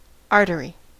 Ääntäminen
Tuntematon aksentti: IPA : /ˈɑɹ.təɹɪ/